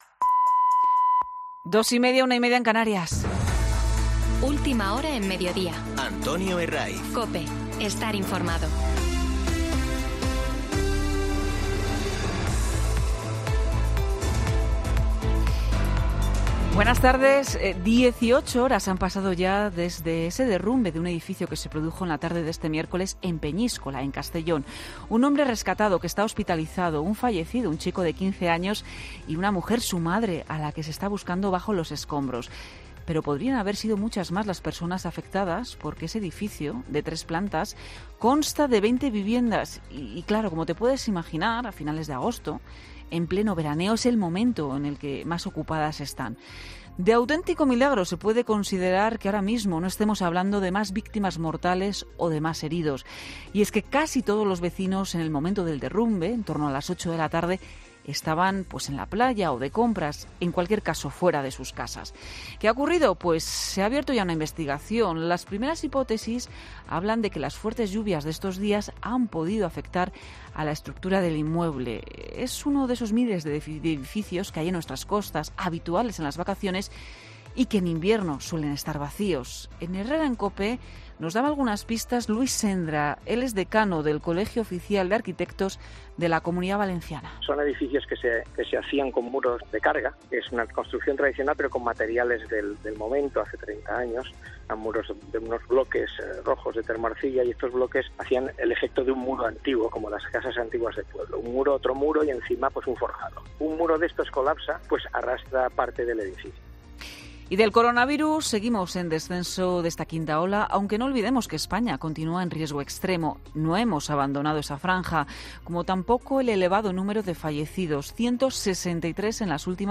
Los aplausos de los simpatizantes tratando de tapar, de que no se oyeran esos abucheos. 17 meses han pasado desde su estallido en nuestro país y hasta ahora el presidente del Gobierno no se había dignado a visitar un centro de nuestros mayores, que no hay que olvidar que han sido los más golpeados por el COVID.